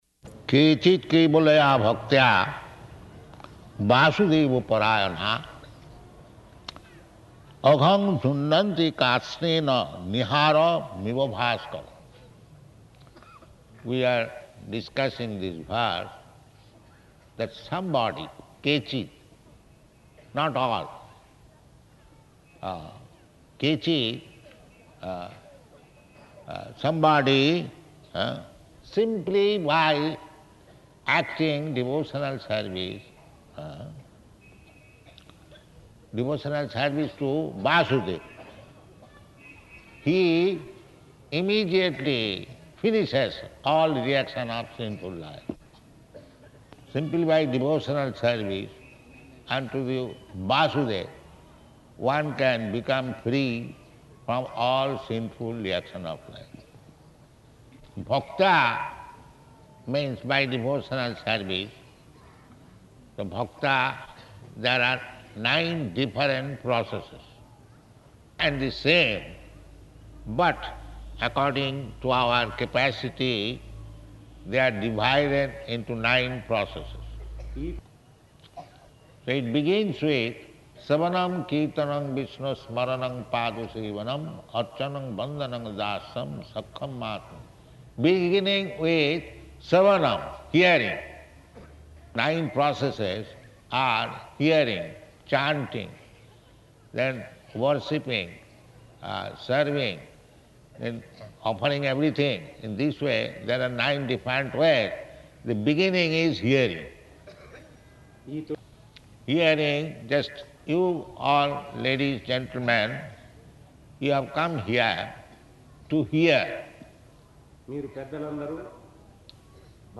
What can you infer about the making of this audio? Location: Nellore